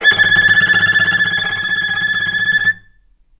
vc_trm-A6-mf.aif